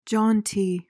PRONUNCIATION:
(JON/JAHN-tee)